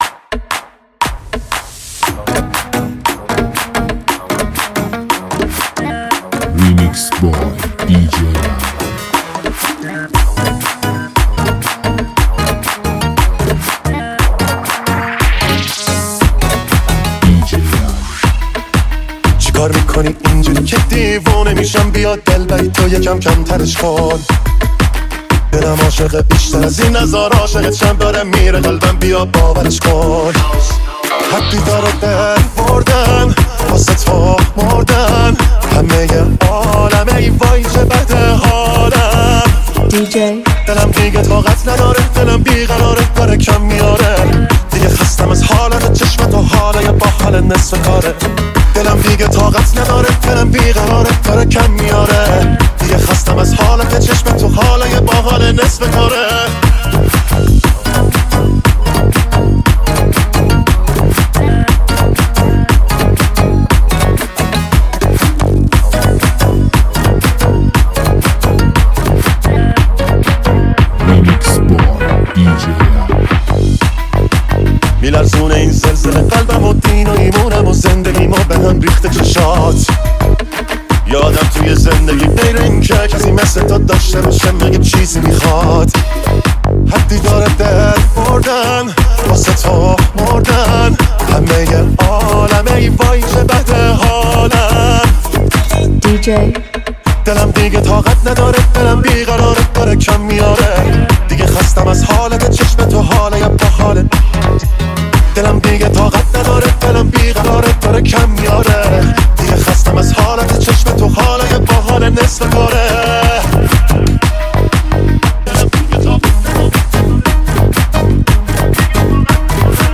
آهنگ شاد و بیس‌دار
بیت‌های مدرن و شاد